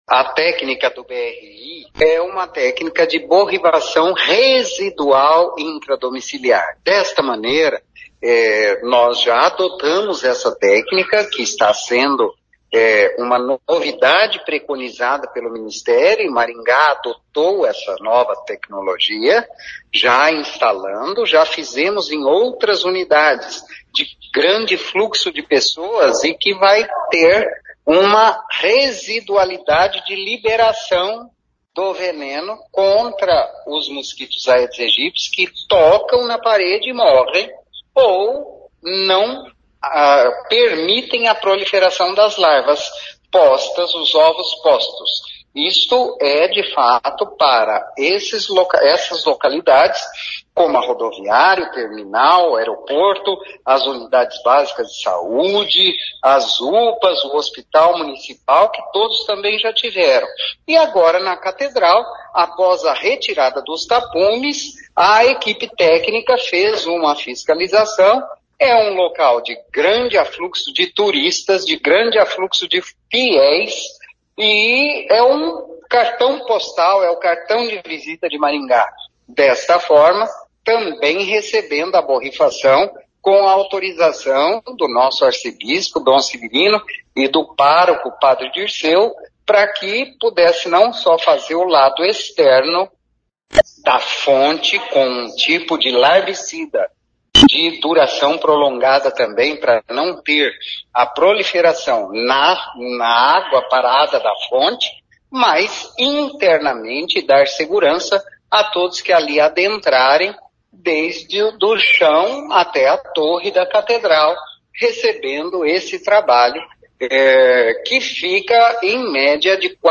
O secretário de Saúde Antônio Carlos Nardi explica que assim como o mosquito se adapta ao ambiente, a tecnologia evolui para combatê-lo.